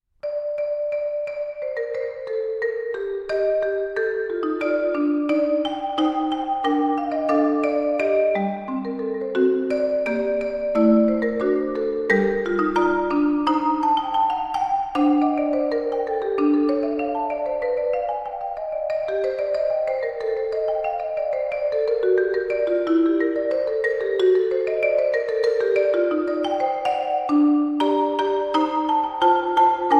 Marimba and Vibraphone